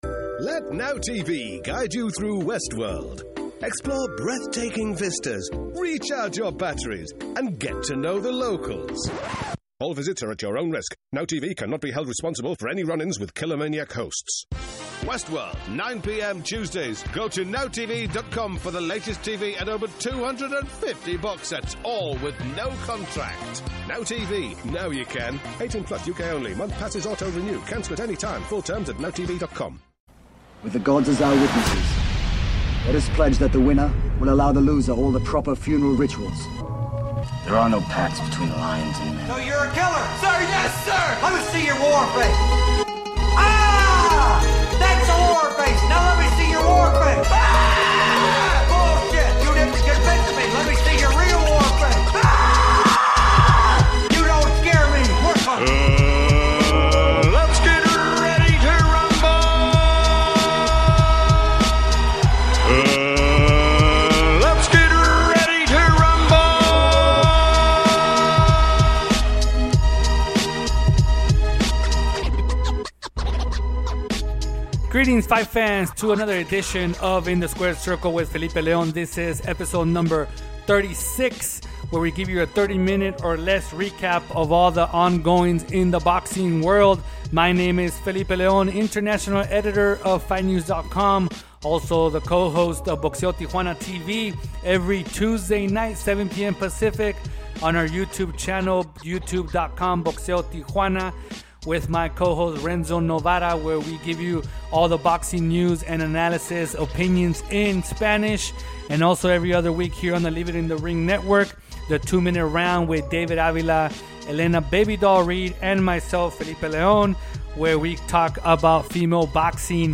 passes the latest news in the sport with a fast pace style of 30 minutes or less.